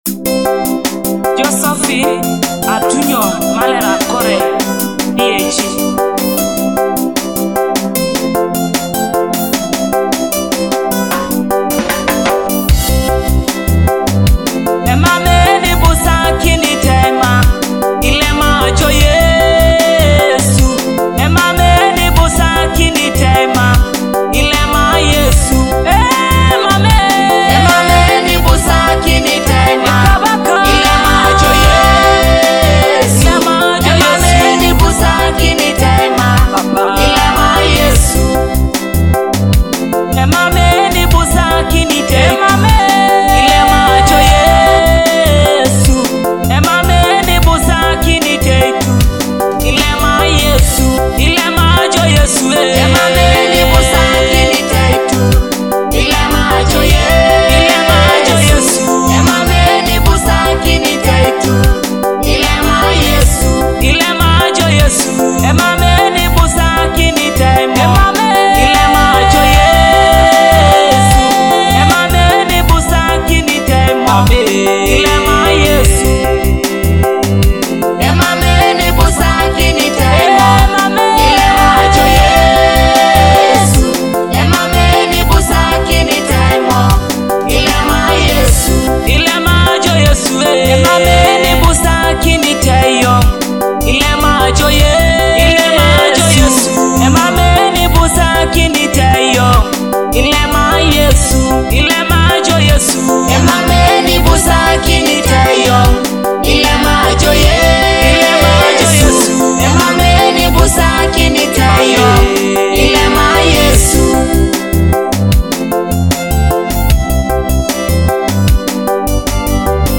joyful praise and worship nonstop